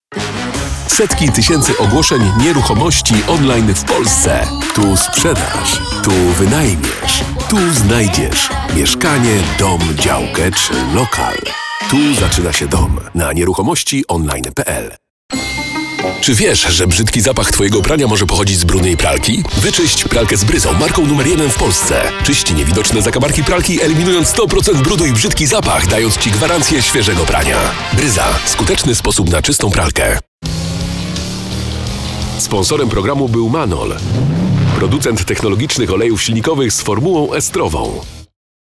deep Polish male voice artist
Male 30-50 lat
Udźwiękowiony spot reklamowy